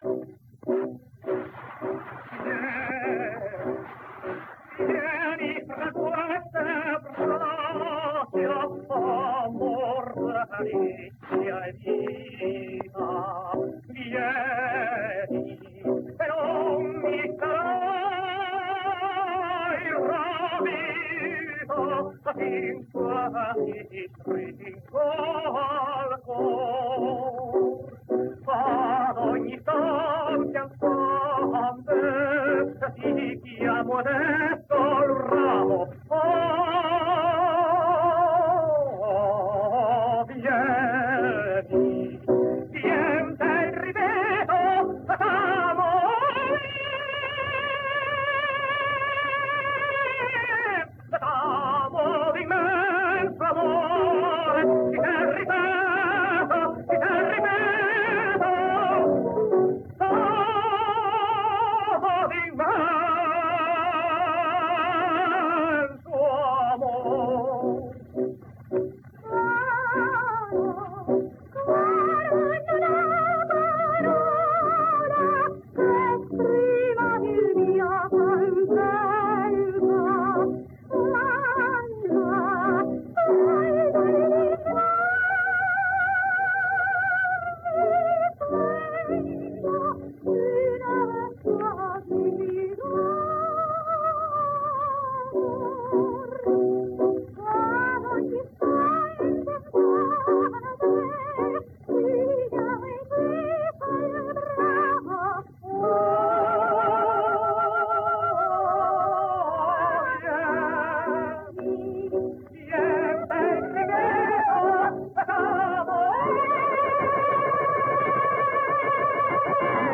He was praised for his easy top notes.